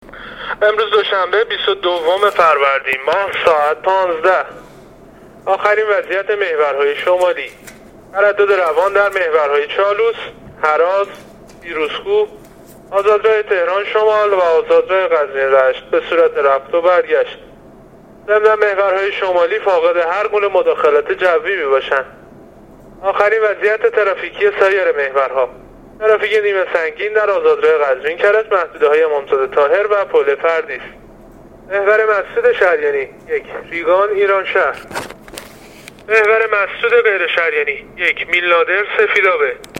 گزارش رادیو اینترنتی از آخرین وضعیت ترافیکی جاده‌ها تا ساعت ۱۵ بیست و دوم فروردین؛